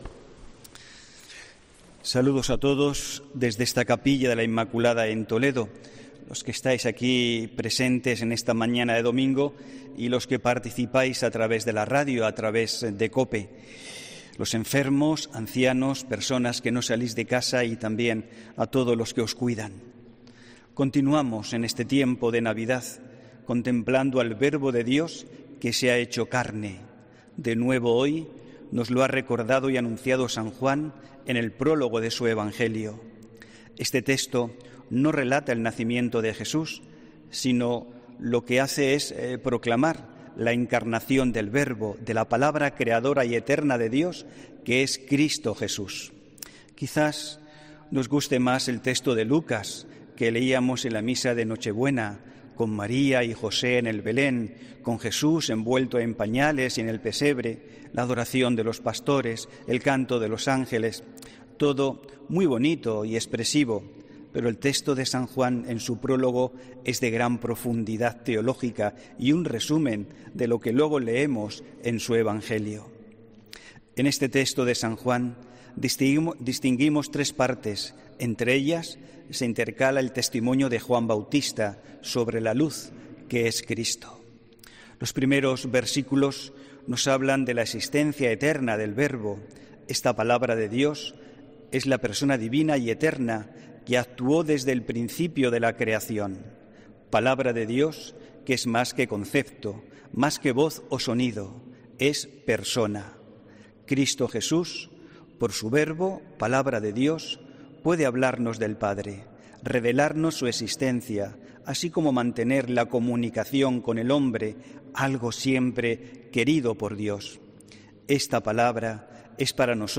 HOMILÍA 2 ENERO 2022